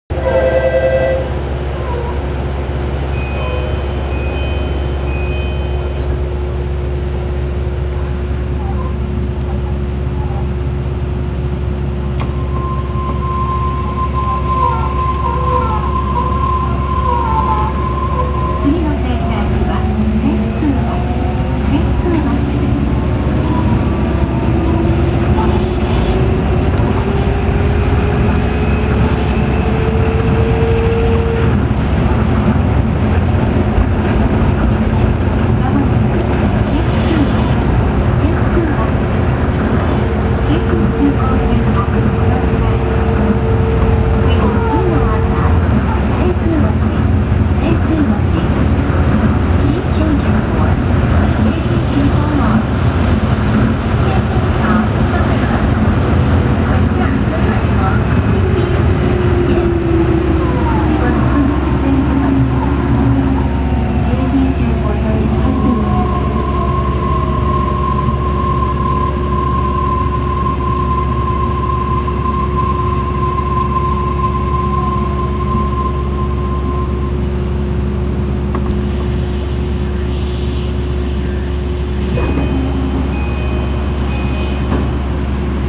・10000形走行音
【羽田空港線】整備場→天空橋（1分25秒：466KB）
近年の日立製IGBTインバータを採用しており、JR東日本のE531系、東武鉄道の60000系などに類似した走行音となっています（非同期音が同じ）。モーター音自体は静かですが、やはりモノレールという特殊な路線である以上ある程度の揺れは感じます。
車内放送の声は今まで通りですが、ドアチャイムはJR東日本タイプの物に変更されました。